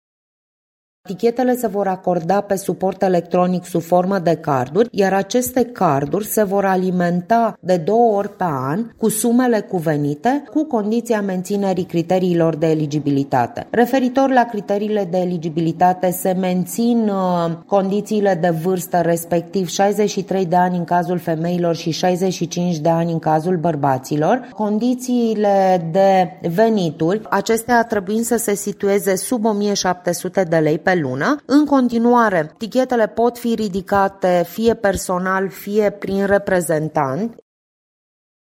Radio România Mureș